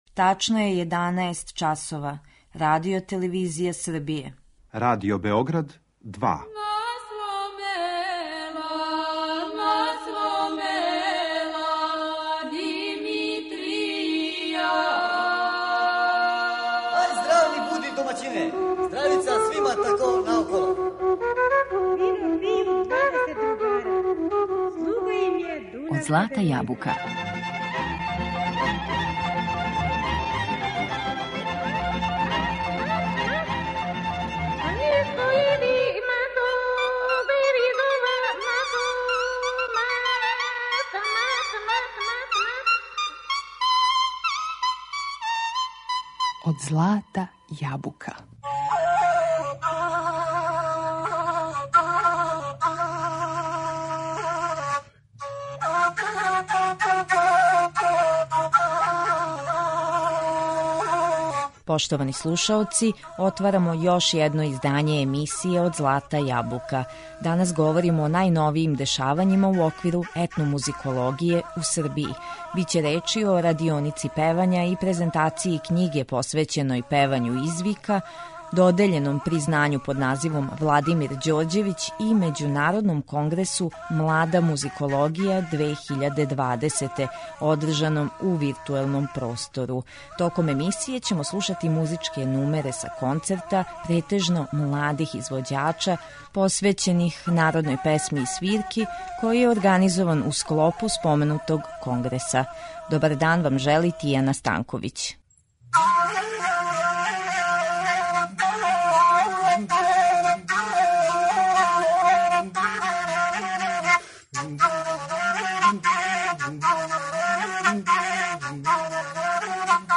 Током емисије ћемо слушати музичке нумере са концерта, претежно младих извођача, посвећених народној песми и свирки, који је организован у склопу споменутог конгреса.